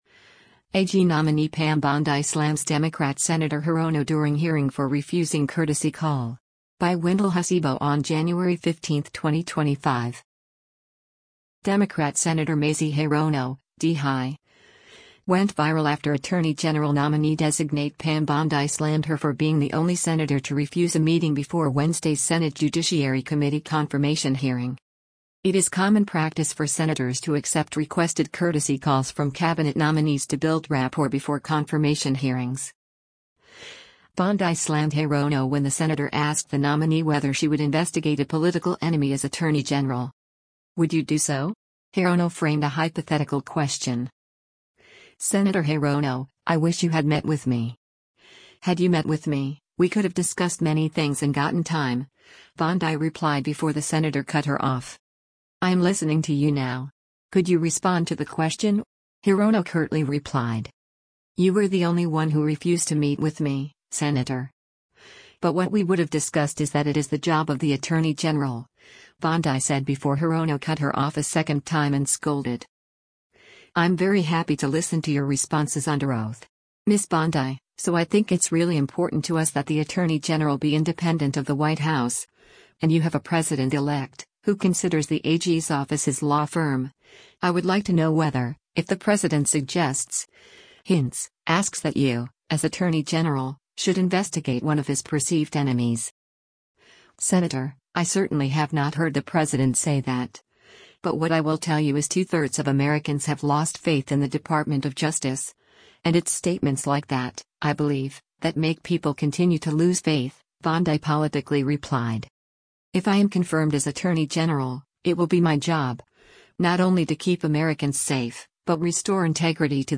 AG Nominee Pam Bondi Slams Democrat Sen. Hirono During Hearing for Refusing Courtesy Call